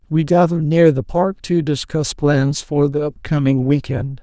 TTS_audio / PromptTTS++ /sample1 /Template2 /Condition /Friends /Adv /loudness /quiet.wav
quiet.wav